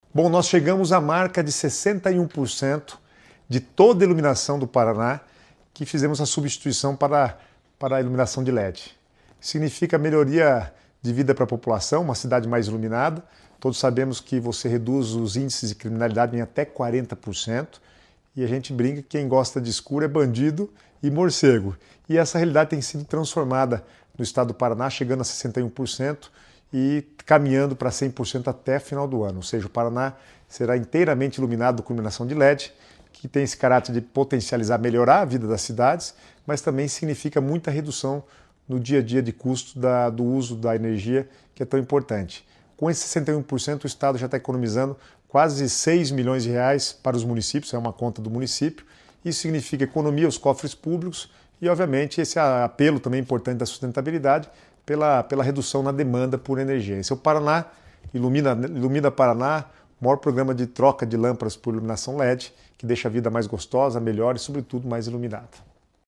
Sonora do secretário das Cidades, Guto Silva, sobre o Ilumina Paraná